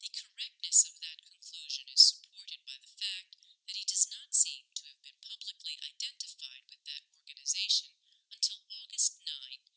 highpass_0.4.wav